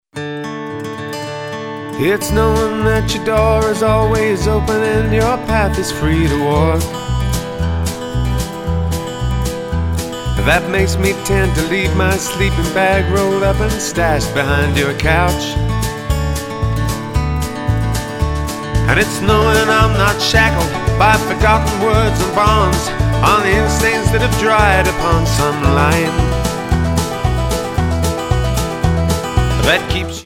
Tonart:D Multifile (kein Sofortdownload.
Die besten Playbacks Instrumentals und Karaoke Versionen .